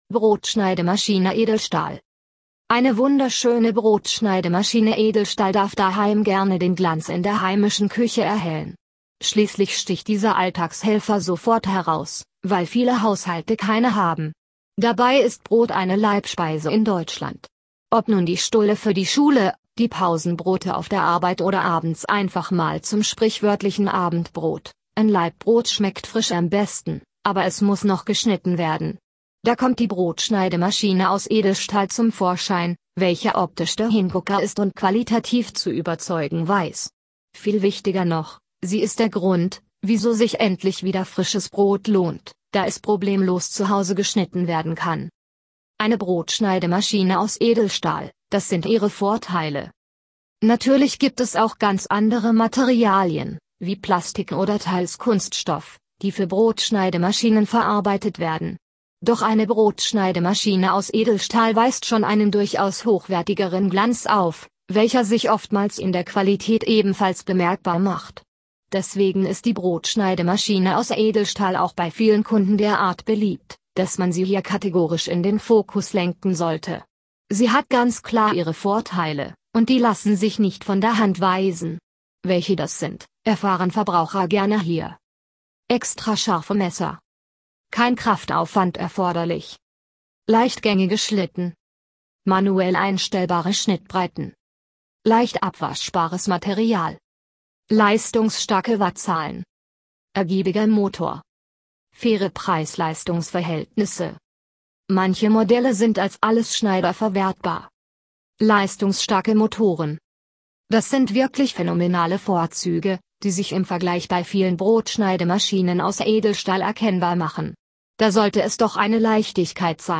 (Audio für Menschen mit Seh- oder Leseschwäche – Wir lesen Ihnen unseren Inhalt vor!)